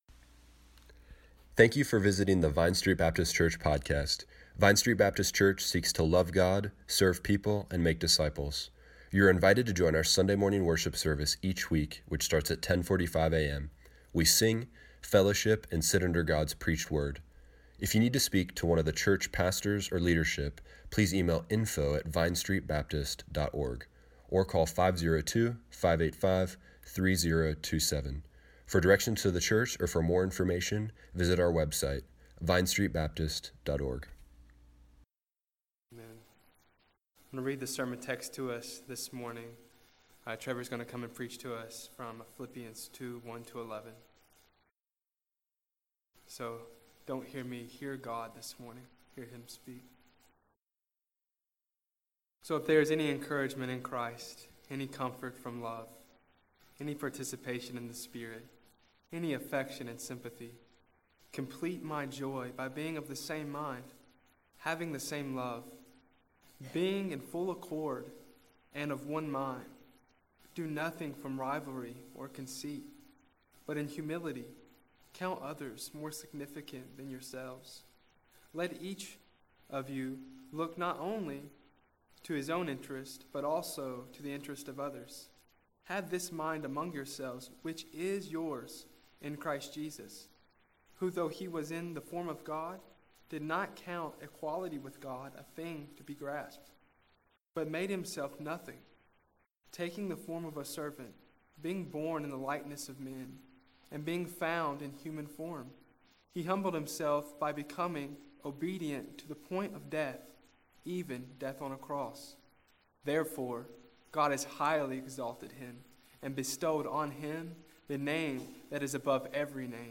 February 11, 2018 Morning Worship | Vine Street Baptist Church